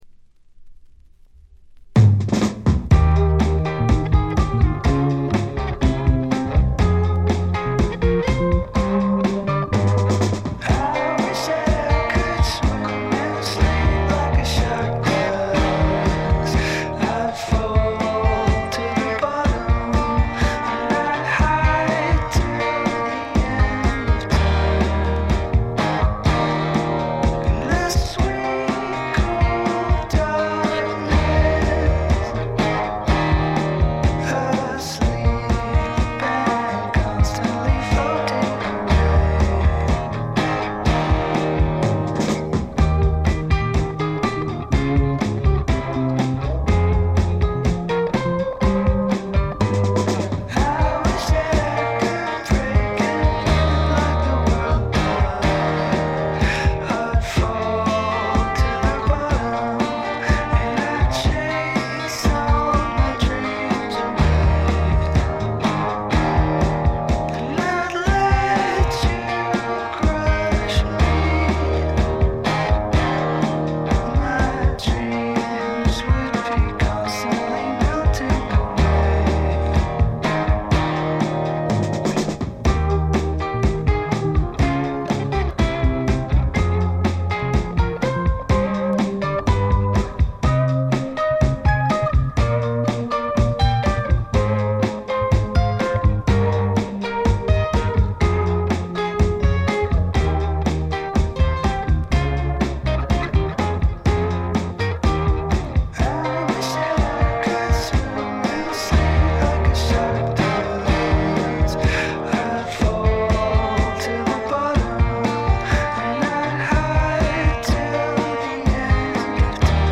試聴曲は現品からの取り込み音源です。
Drums